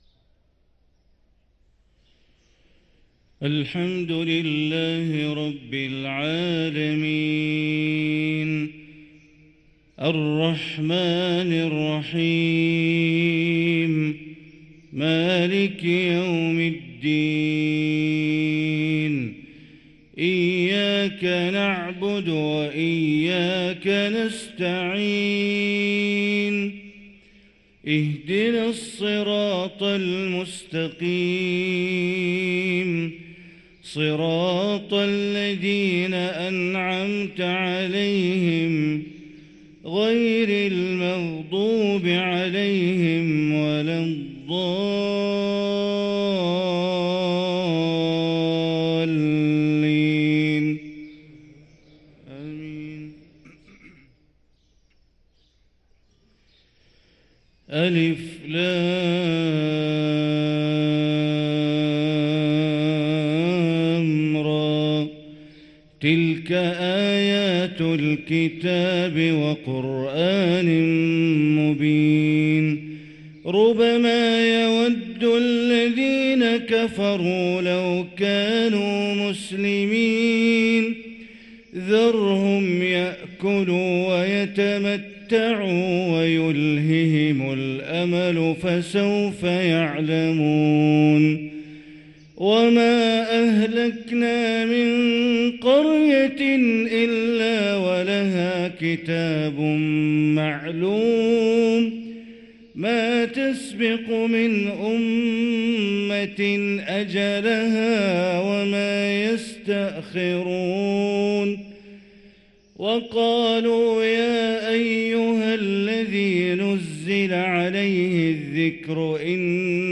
صلاة الفجر للقارئ بندر بليلة 7 جمادي الآخر 1445 هـ